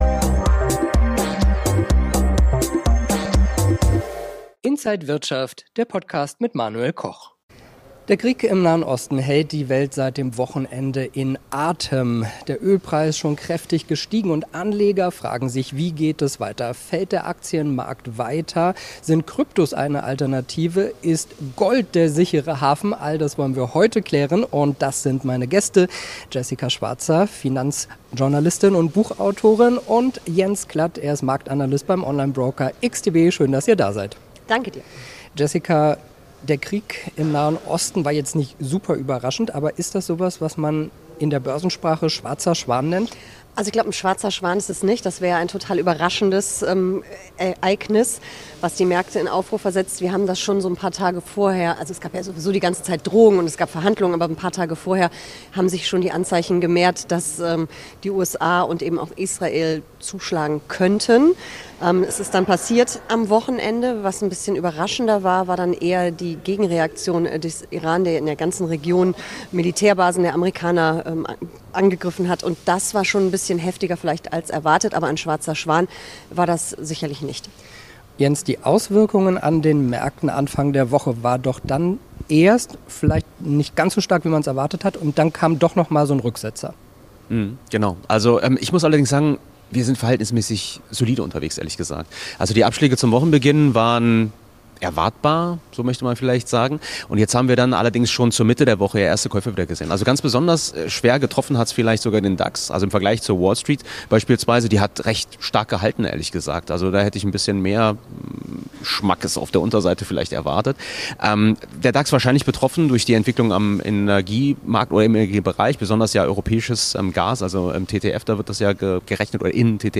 Interview
an der Frankfurter Börse